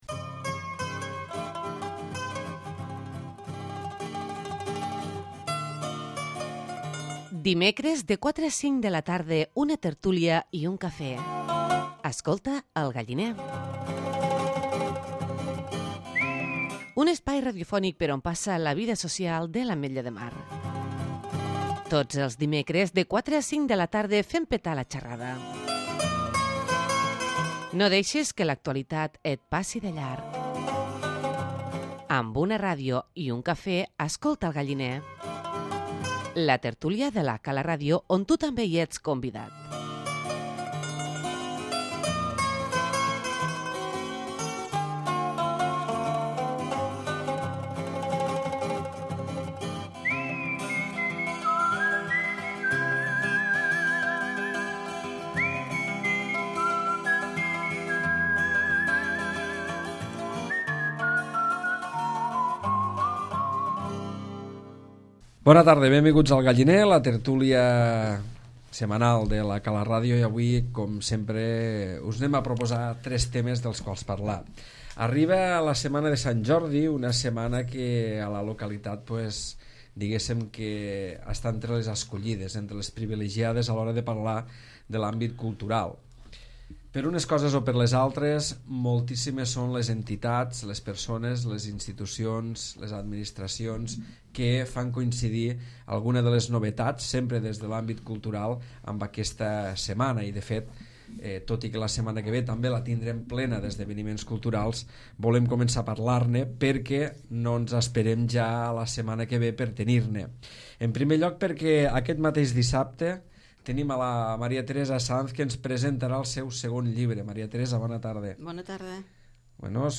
La tertúlia setmanal del Galliner dedica aquesta setmana el seu espai a parlar de musica, literatura i teatre